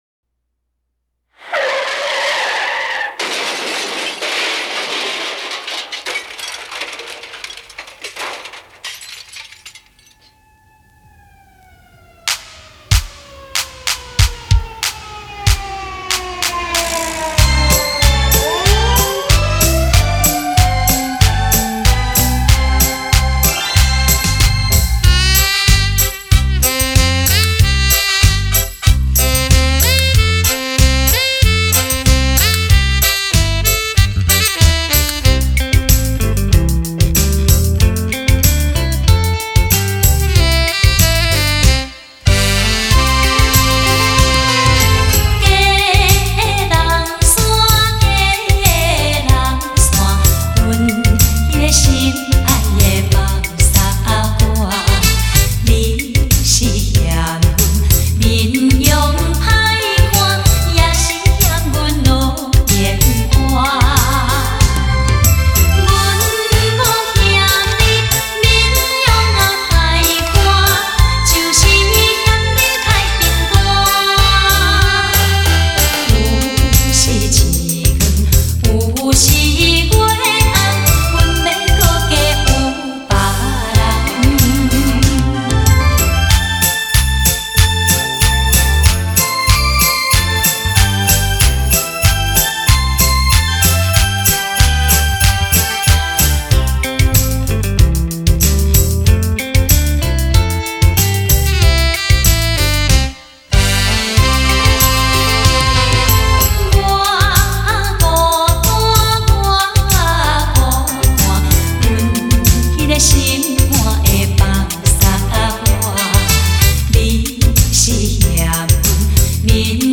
动向效果